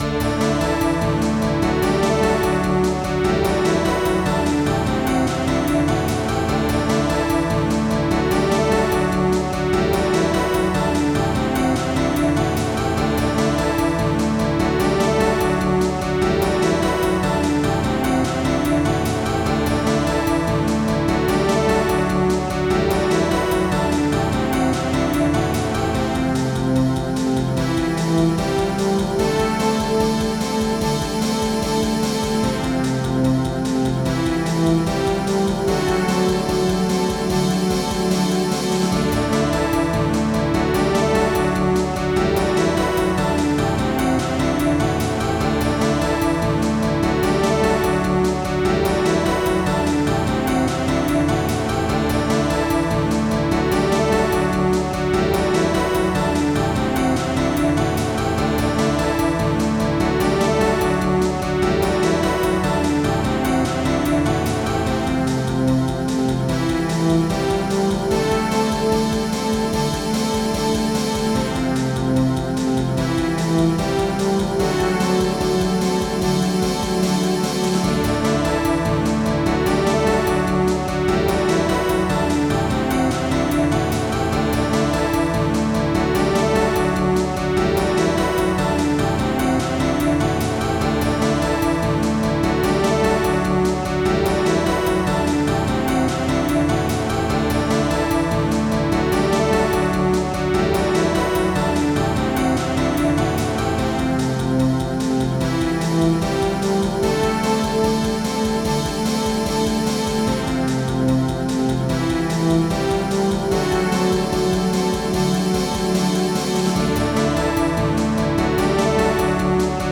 MIDI Music File